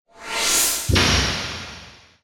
/ F｜演出・アニメ・心理 / F-03 ｜ワンポイント1_エフェクティブ
ウィーシバーン